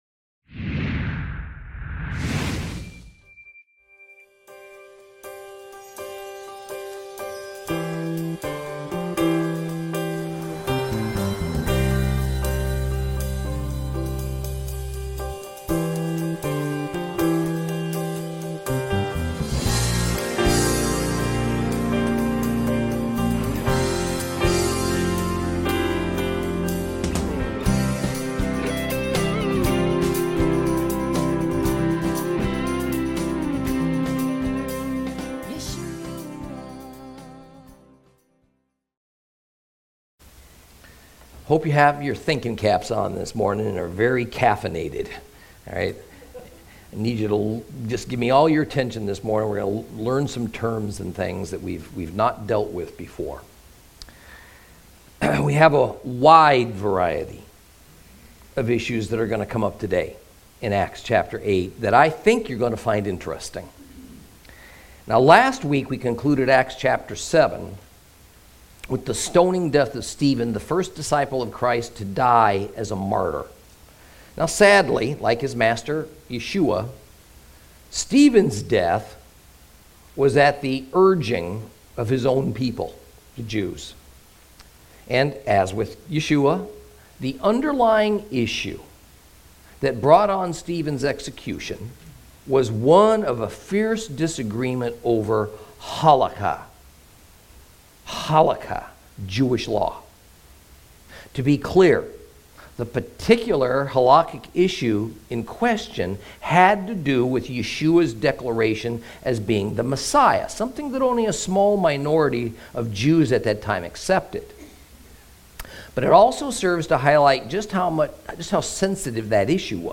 Acts Lesson 19 – Chapter 8